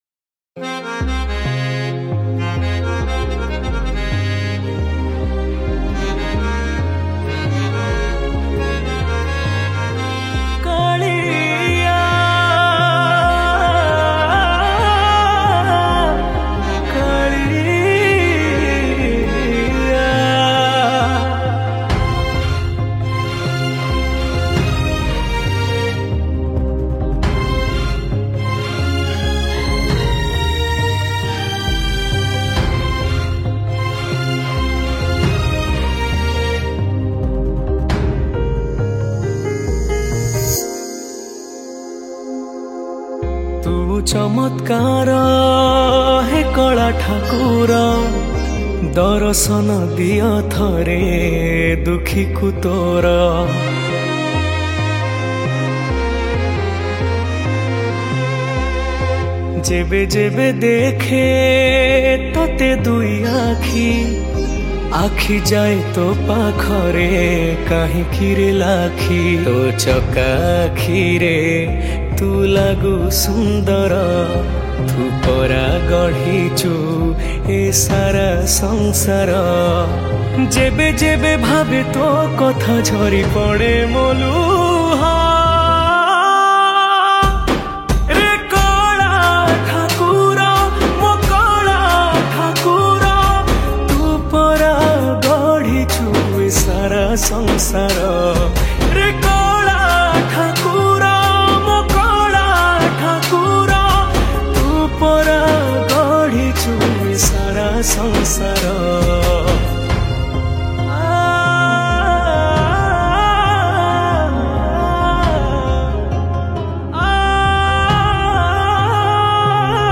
Odia Bhajan Song 2024 Songs Download